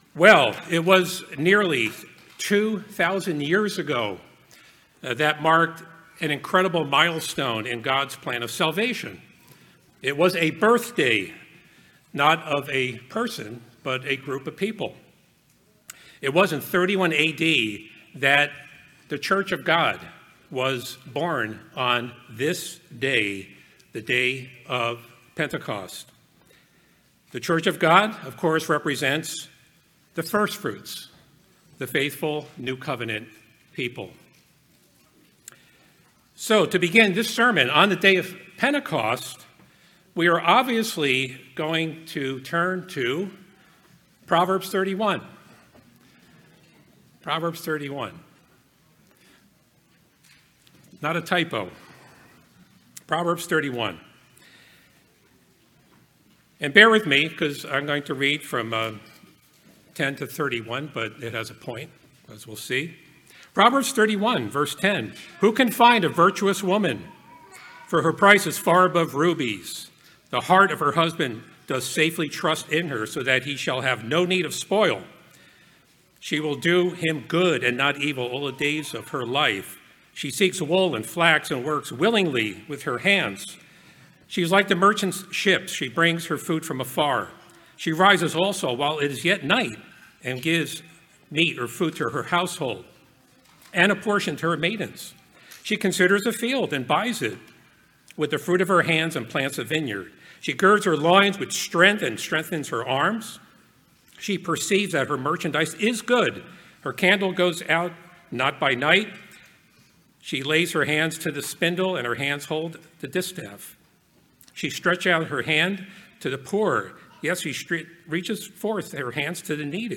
The book of Ruth is traditionally tied to the Feast of Weeks. In this sermon, the life and character of Ruth is examined and compared to the proverbs 31 virtuous woman.